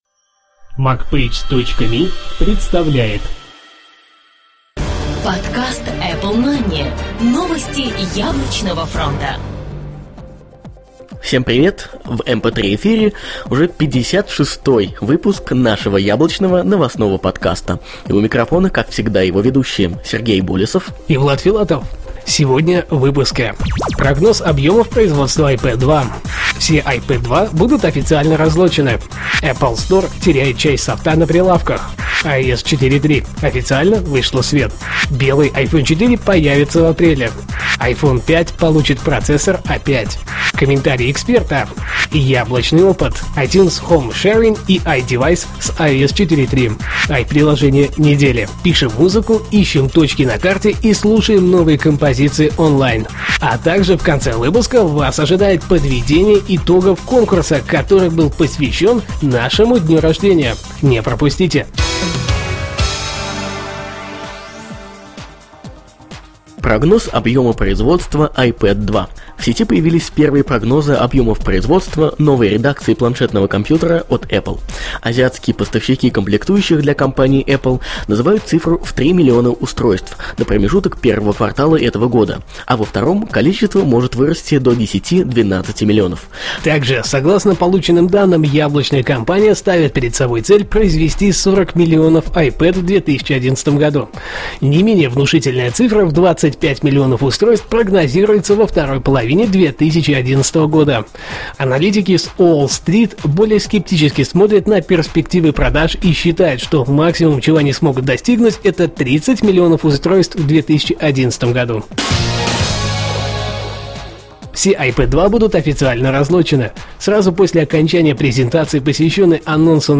Жанр: новостной Apple-podcast
stereo